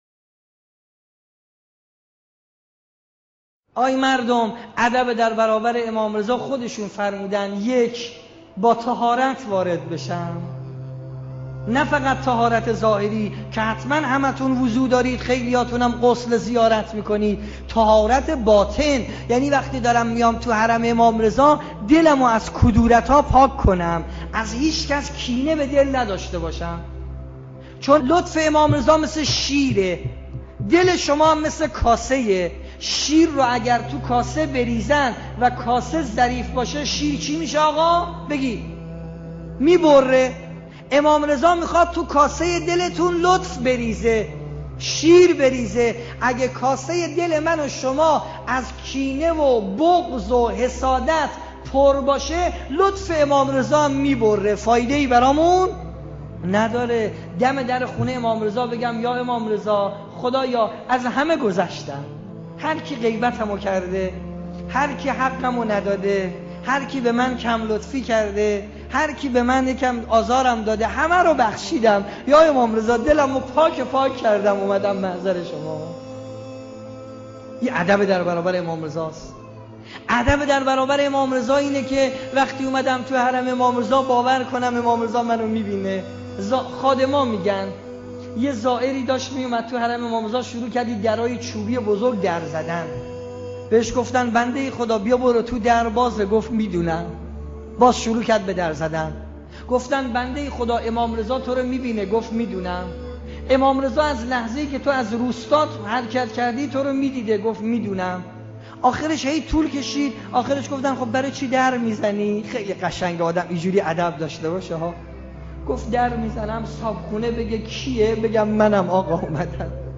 سخنرانان